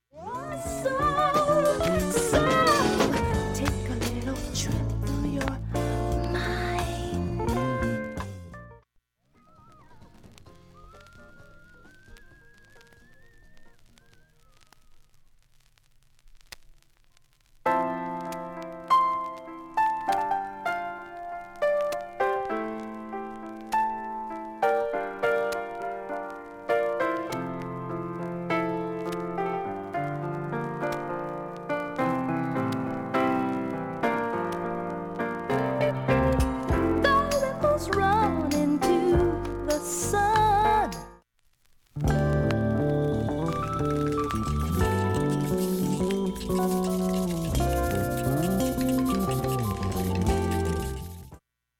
結構クリアな音質。
終わりフェイドアウト部に
かなりかすかなプツが９回出ます